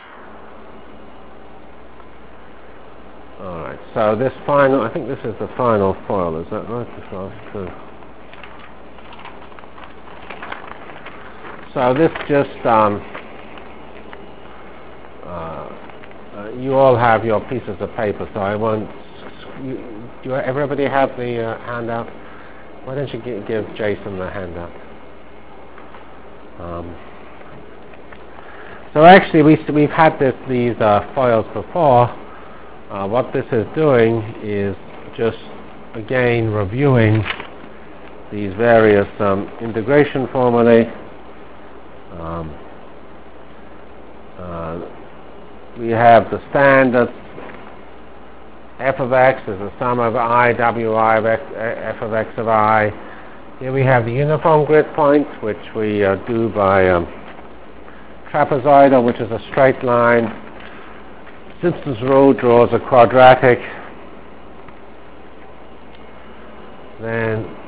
From CPS615-End of Basic Overview of Random Numbers and First Part of Monte Carlo Integration Delivered Lectures of CPS615 Basic Simulation Track for Computational Science -- 22 October 96.